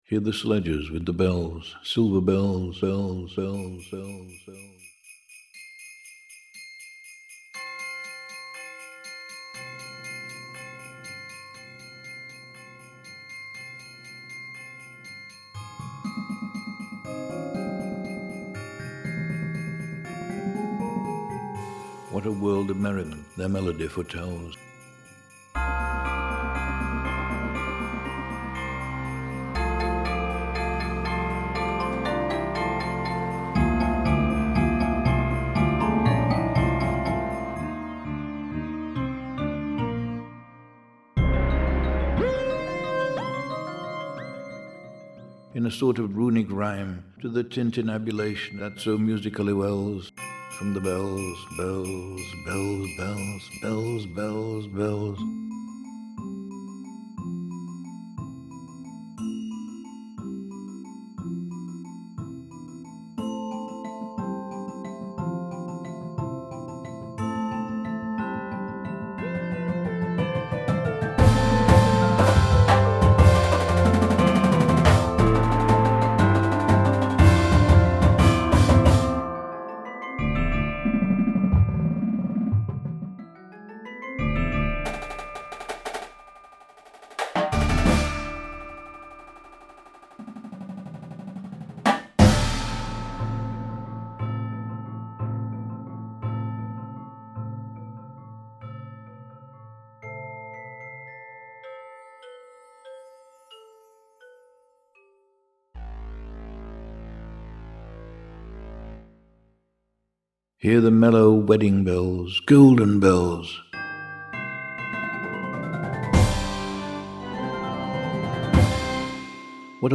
modern Indoor Percussion Show